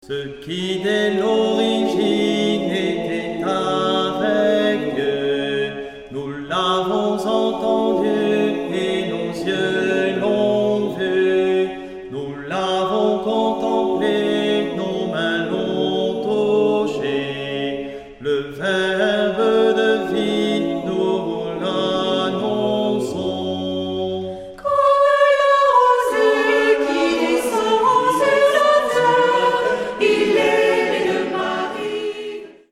Chants de Noël
chantent à 4 voix